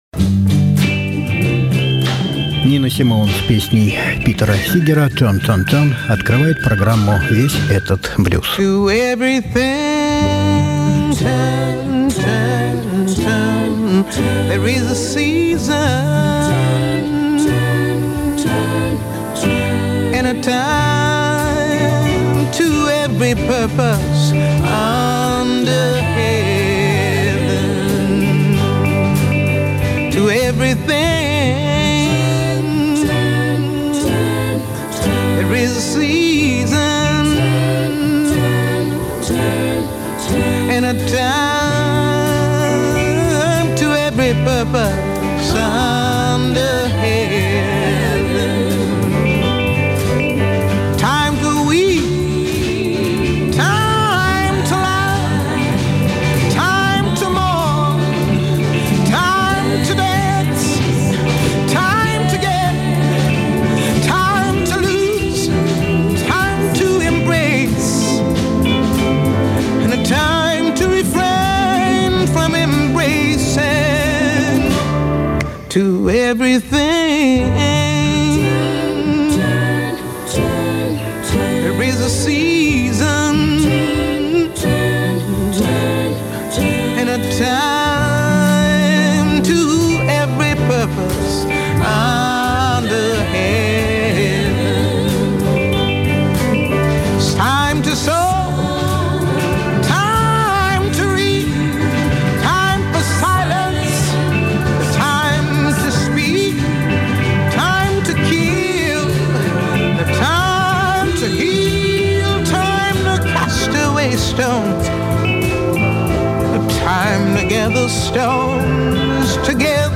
Сегодня мы поговорим о его творческом пути и послушаем песни в его исполнении, а также его песни в исполнении других музыкантов. 1.